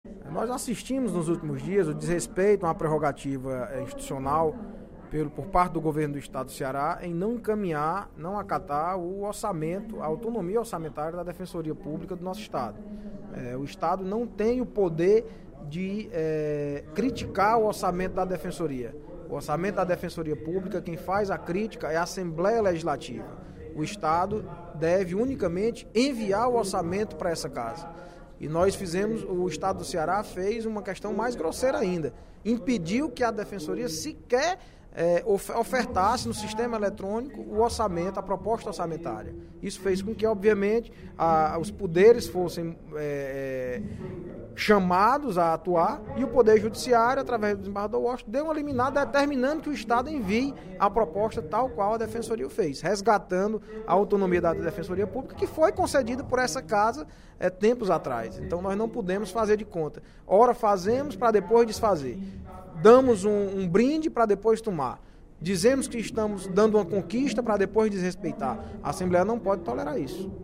O deputado Audic Mota (PMDB) criticou o Governo do Ceará, no primeiro expediente da sessão plenária da Assembleia Legislativa desta quarta-feira (04/11), por “não acatar a autonomia orçamentária da Defensoria Pública do Estado”.